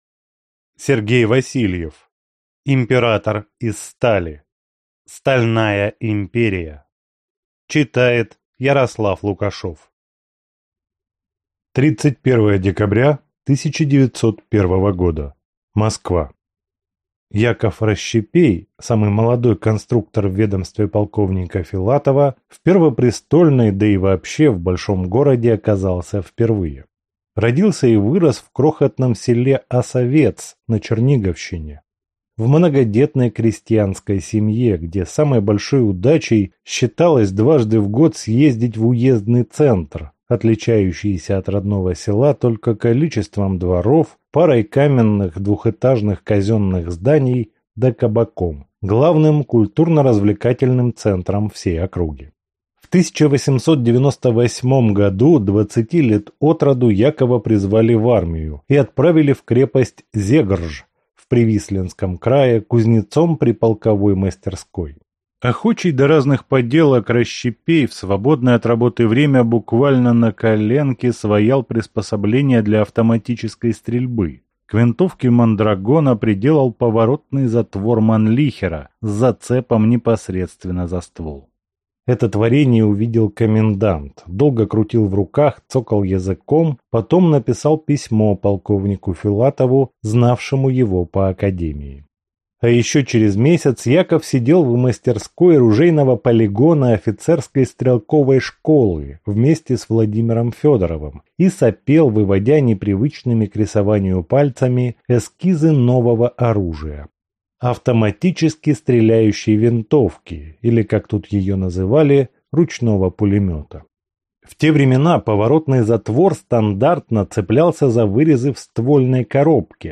Аудиокнига Император из стали. Стальная империя | Библиотека аудиокниг